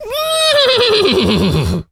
Animal_Impersonations
horse_neigh_03.wav